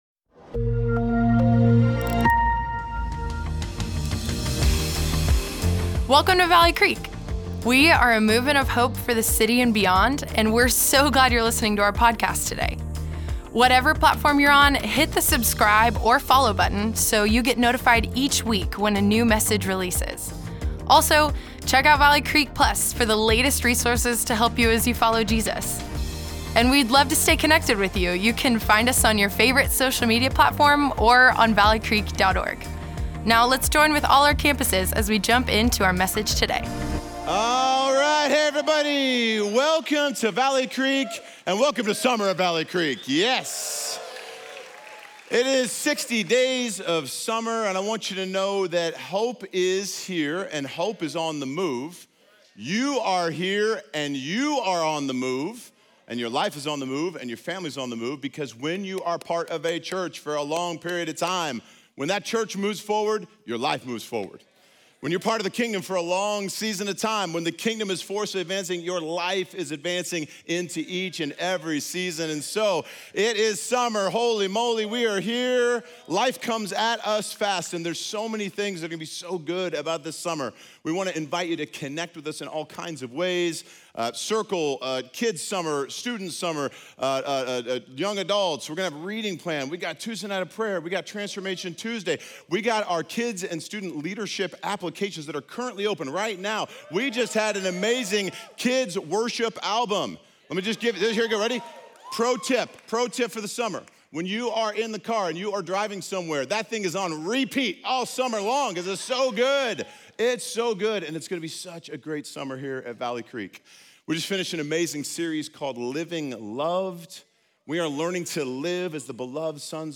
Weekend Messages